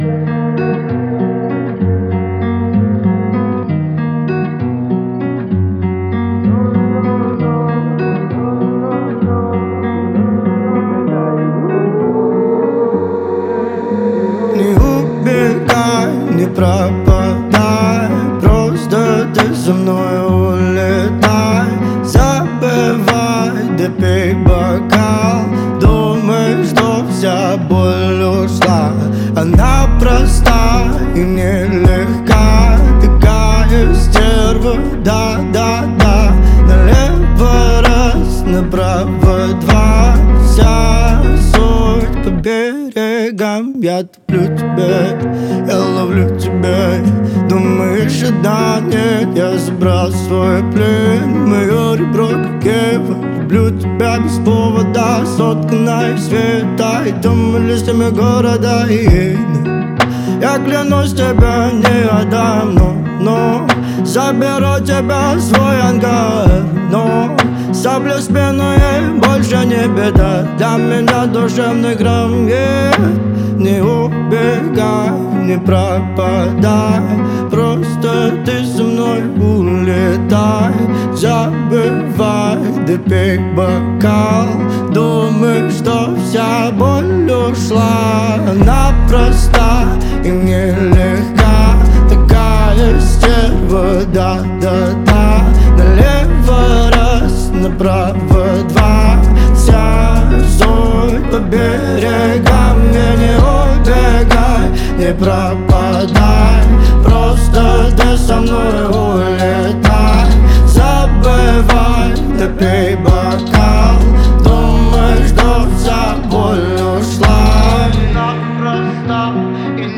в жанре поп-музыки с элементами R&B